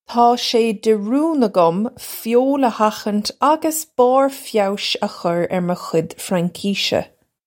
Taw shay deh roon uggum fyole uh ha-hinch uggus bar fyowsh uh khurr urr muh khudge Fran-kishuh.
This is an approximate phonetic pronunciation of the phrase.